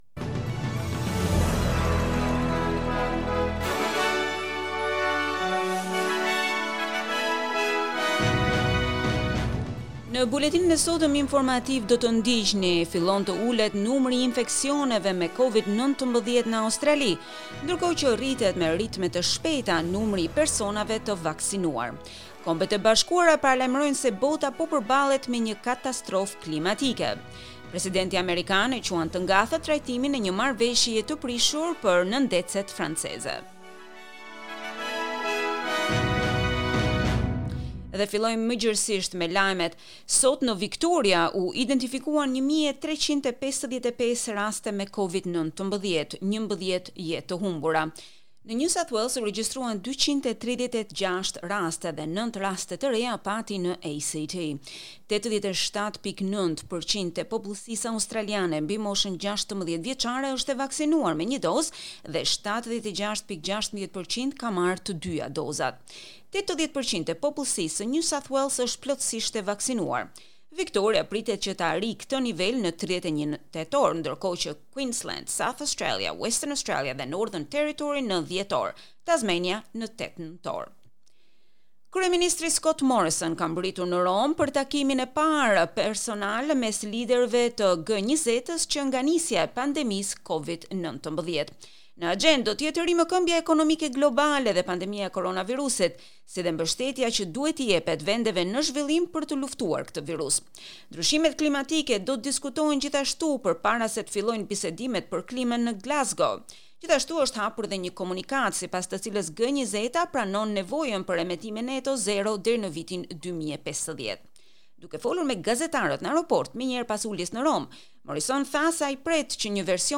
Edicioni Informativ - SBS Shqip Source: SBS